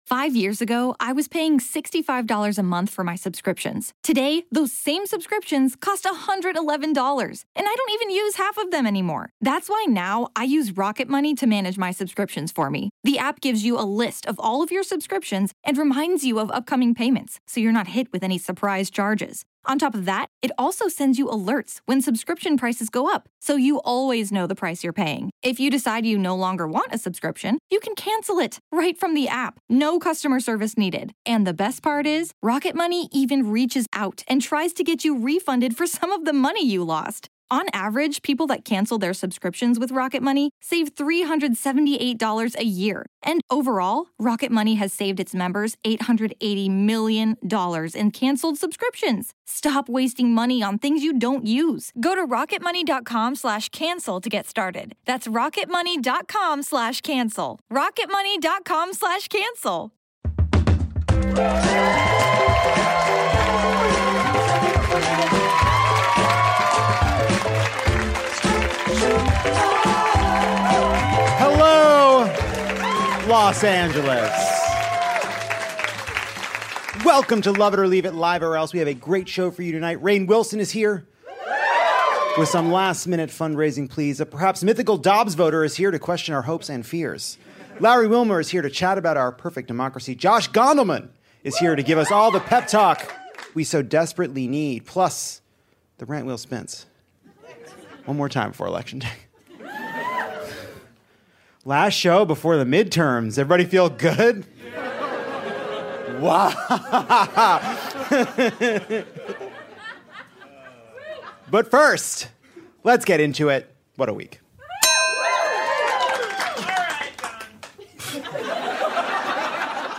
Midterms are making the kids squirm at this, our last Lovett Or Leave It before we see you next Tuesday at the polls. Larry Wilmore looks at Biden’s closing message, while Rainn Wilson screams for your dollars on behalf of the Democrats.